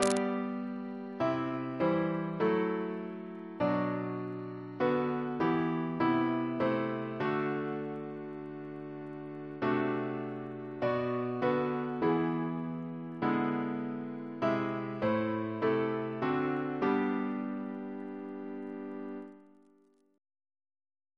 Double chant in G Composer: Francis Jackson (1917-2022) Reference psalters: ACP: 228; RSCM: 79